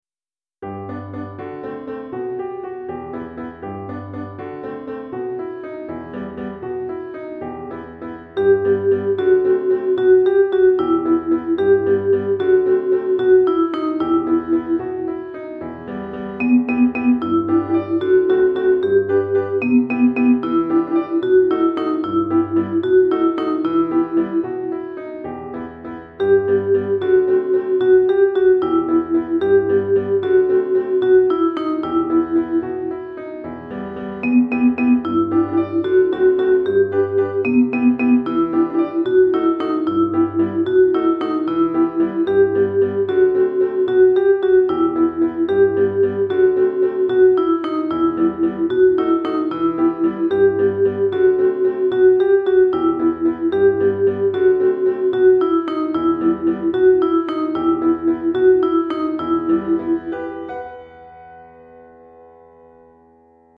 Chorale d'Enfants et Piano ou Guitare